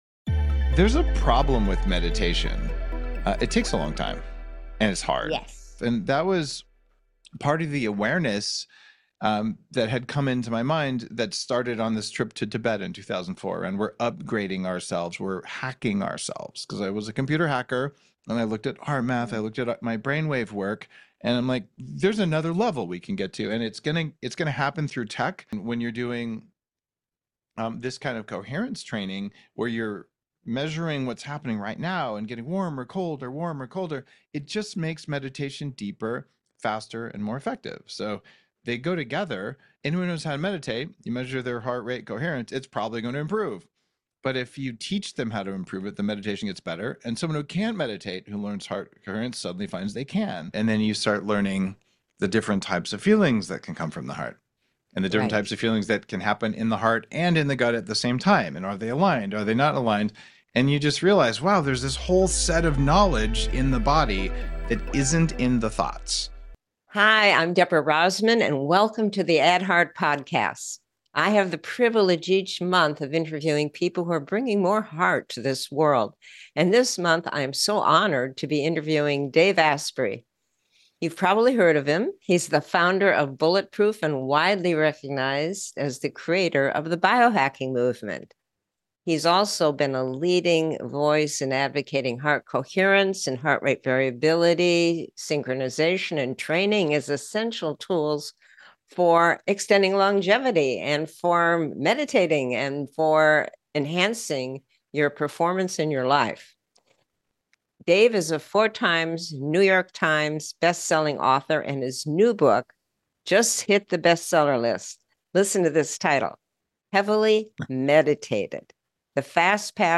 Guest: Dave Asprey What if meditation didn’t have to be so hard—or take years of practice to see results?